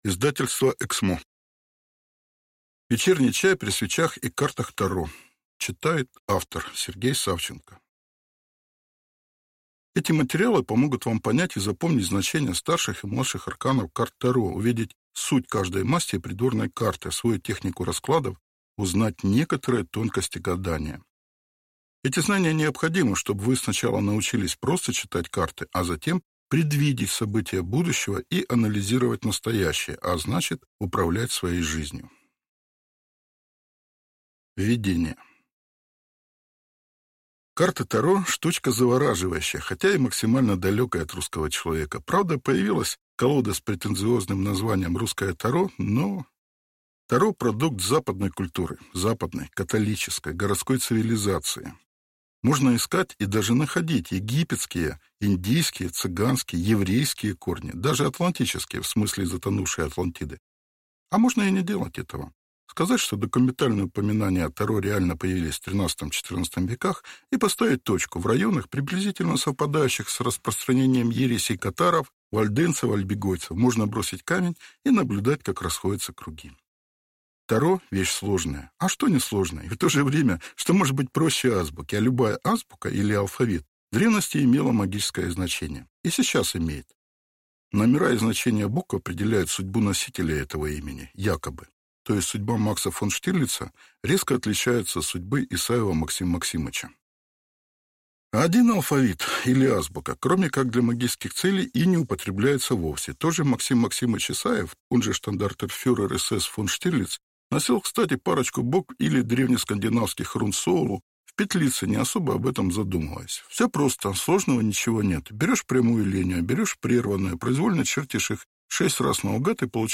Аудиокнига Вечерний чай при свечах и картах Таро. Избранные эссе | Библиотека аудиокниг